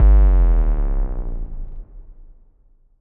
Free Bass